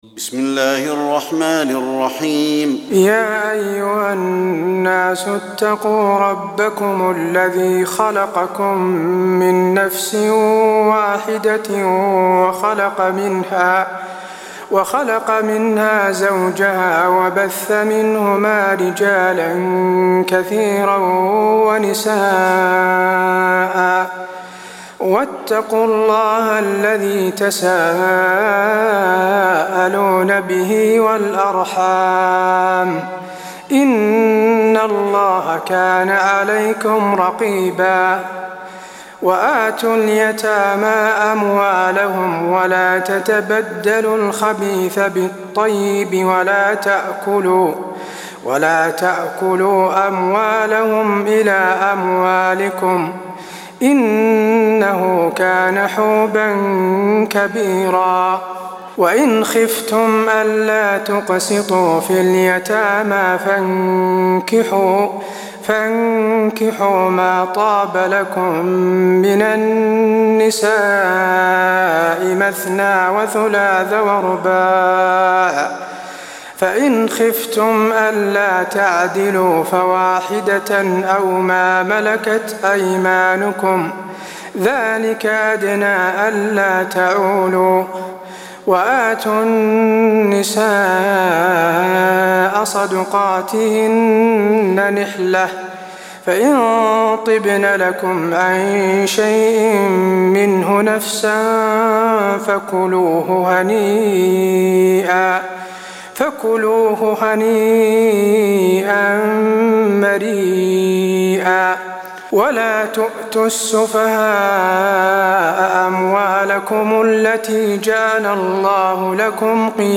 تراويح الليلة الرابعة رمضان 1423هـ من سورة النساء (1-22) Taraweeh 4 st night Ramadan 1423H from Surah An-Nisaa > تراويح الحرم النبوي عام 1423 🕌 > التراويح - تلاوات الحرمين